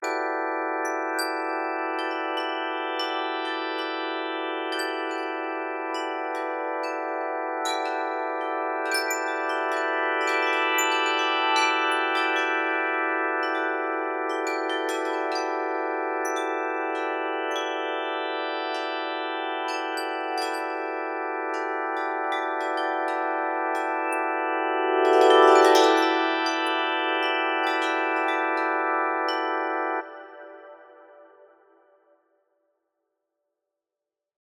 Sweeping around clockwise from the top of the image, the scan encounters Chandra’s X-rays and plays them as single-note wind chimes.